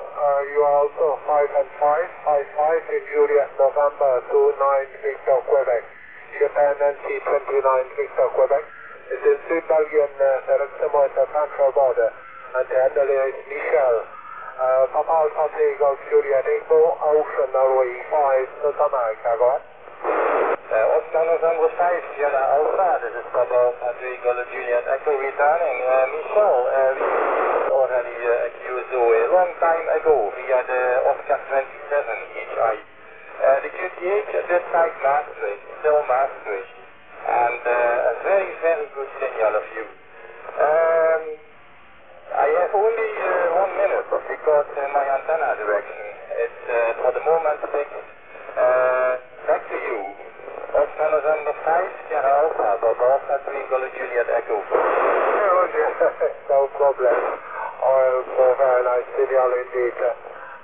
Satellite QSO ( Some very short Audio Fragments concerning Firsts via Satellites )
Remark : the taperecorder had been connected ( directly ) to the Alinco tranceiver DR 510E ( concerning UO-14 and AO-27 in mode J ).
Apologies because of the audio quality.
Further, the taperecorder was very noisy. Because of the compression, most ( mono ) audio files ( sample rate = 6000, resolution = 8 bits ) delivered a bad signal noise ratio.